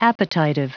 Prononciation du mot appetitive en anglais (fichier audio)
Prononciation du mot : appetitive